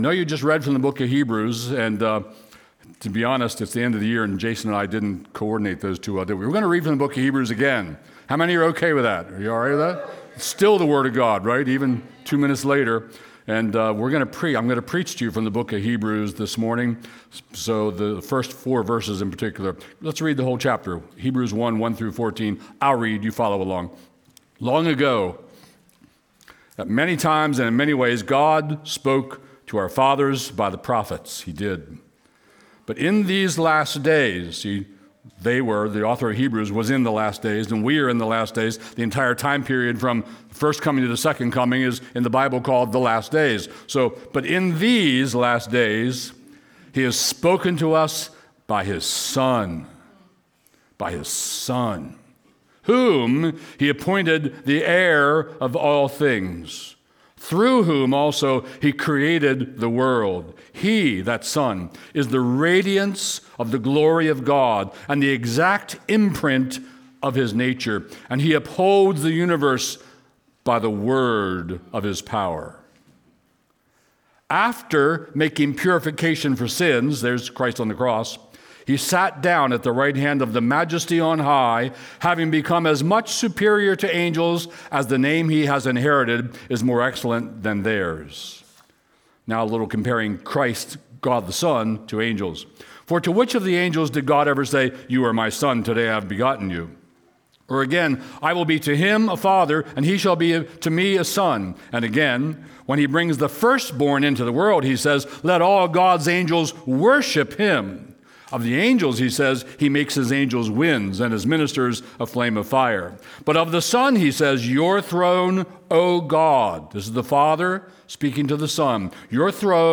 Stream Sermons from Cornerstone Harford County